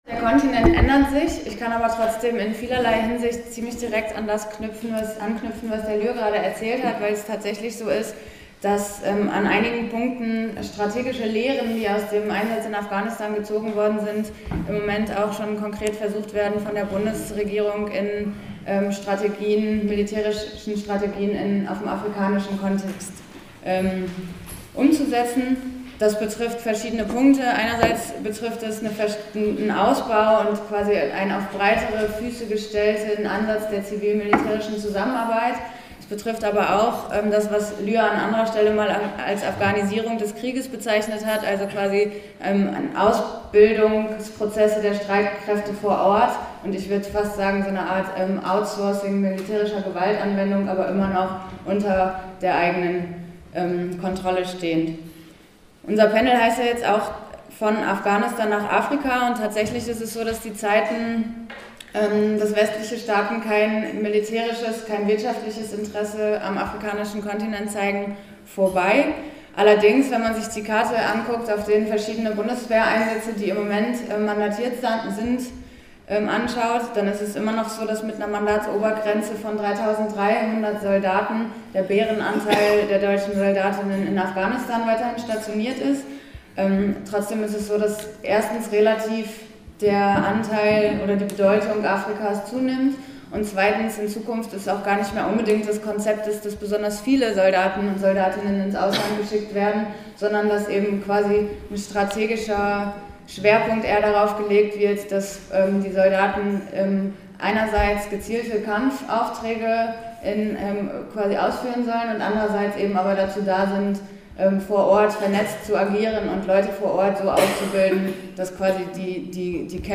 geht in ihrem Vortrag beim IMI-Kongress 2014 auf die Afrika-Politik der Bundesregierung ein